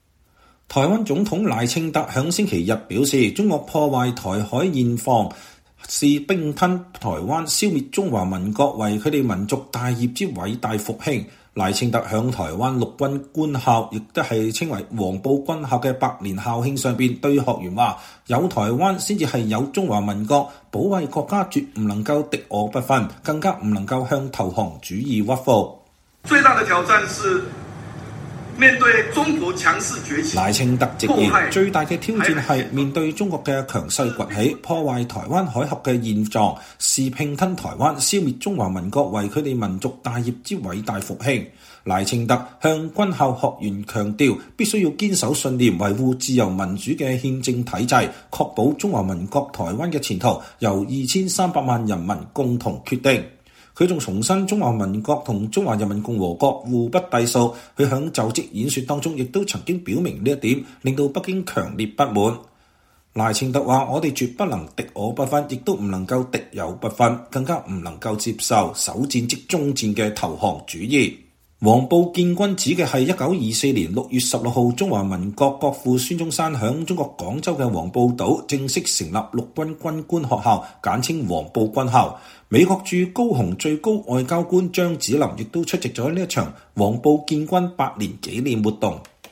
台灣總統軍校演說稱 中國視“併吞與消滅台灣”為民族事業
賴清德6月16日在台灣南部城市高雄主持黃埔軍校建校百年紀念活動。